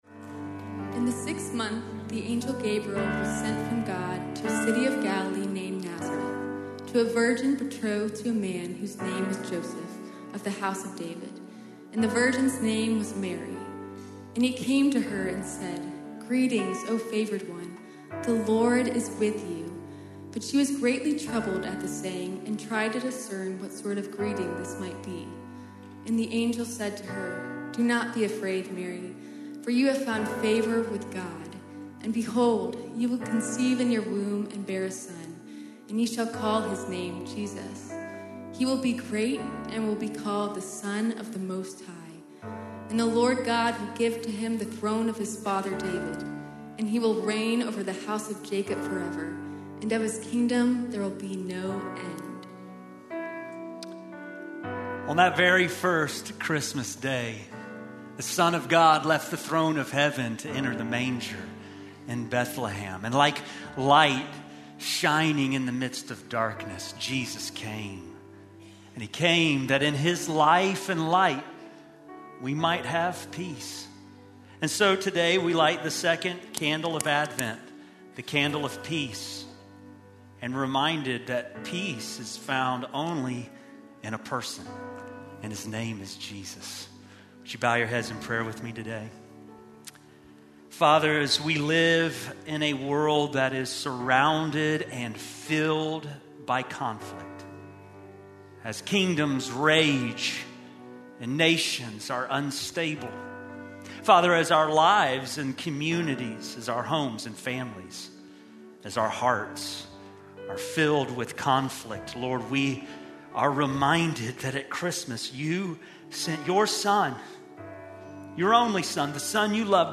Most Recent Worship Service.